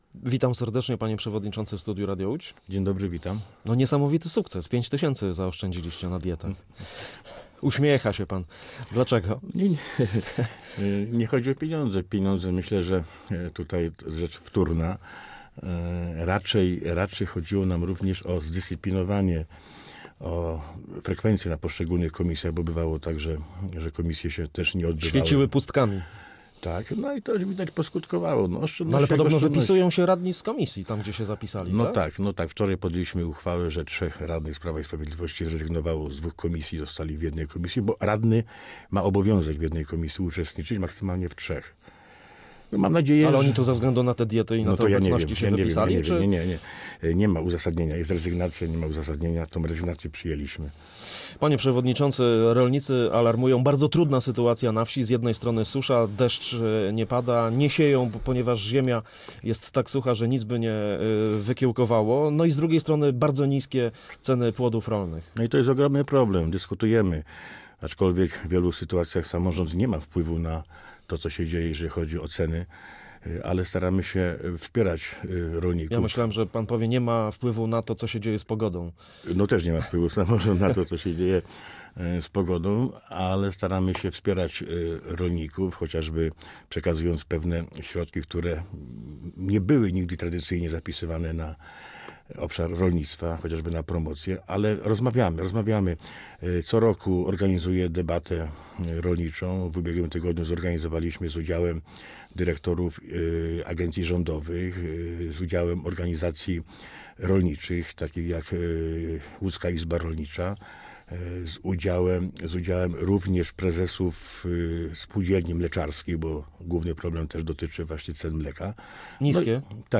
Gościem Radia Łódź był przewodniczący Sejmiku Województwa Łódzkiego Marek Mazur.